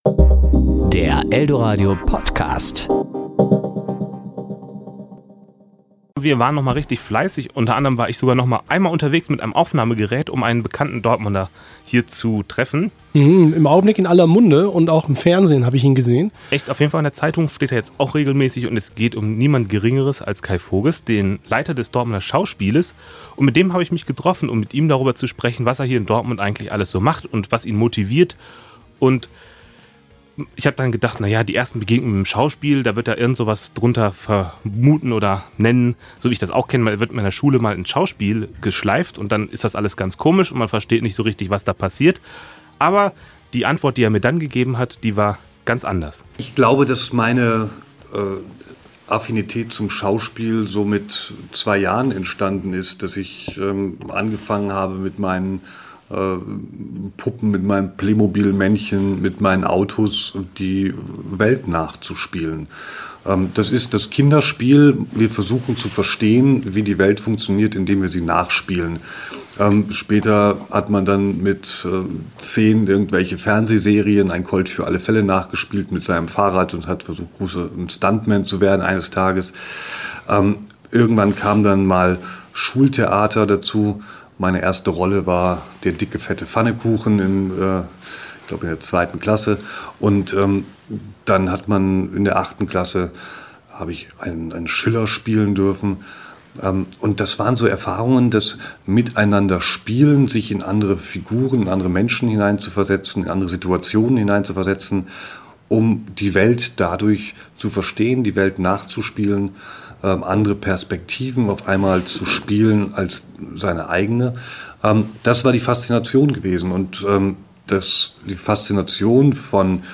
Serie: Interview